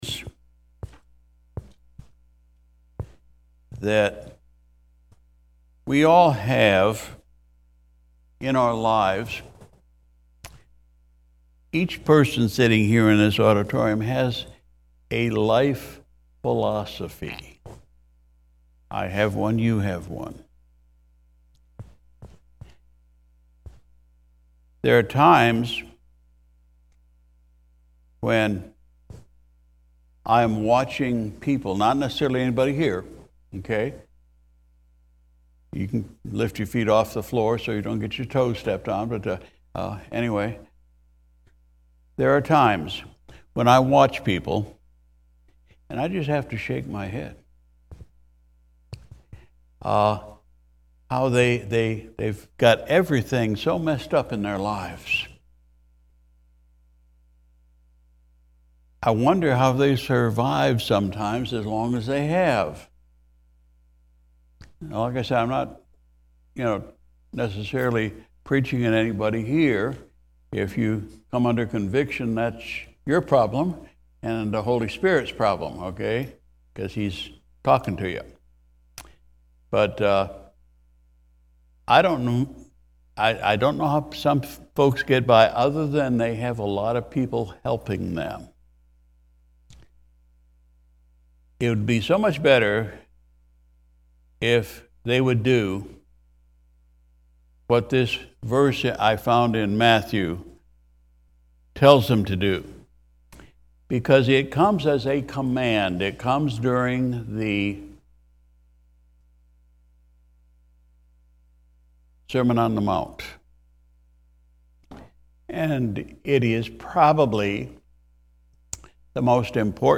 March 26, 2023 Sunday Morning Service Pastor’s Message: “Putting God First”